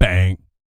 BS BANG 04-L.wav